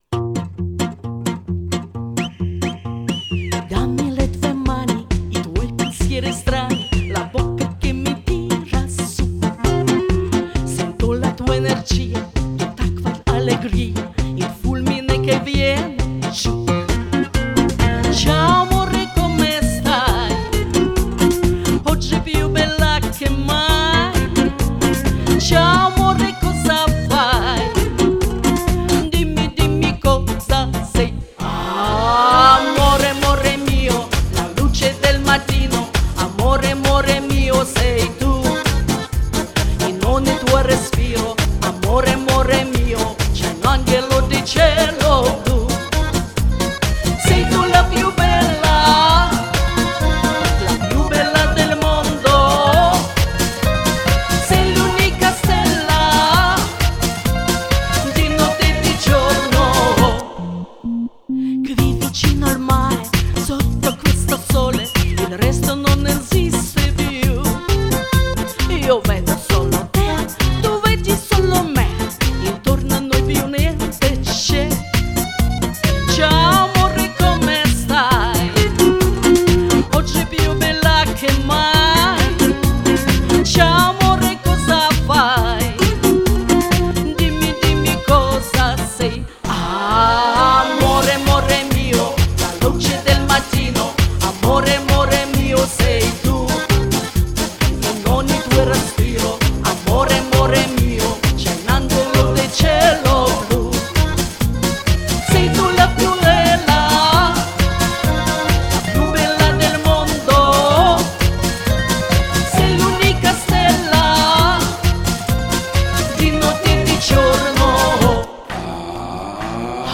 мягкое нежное пение..завораживает